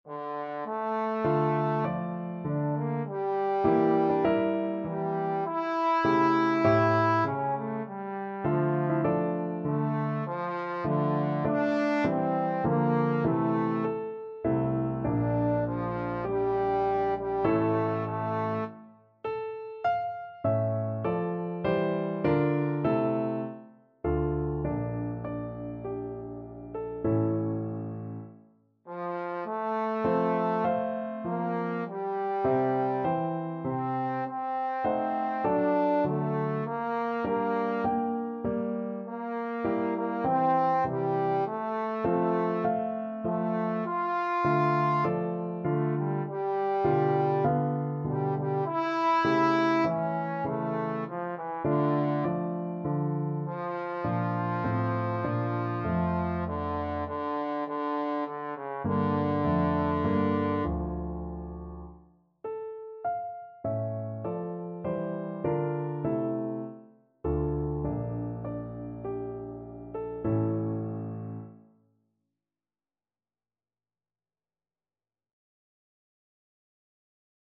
Trombone
Largo =c.100
D minor (Sounding Pitch) (View more D minor Music for Trombone )
4/4 (View more 4/4 Music)
Classical (View more Classical Trombone Music)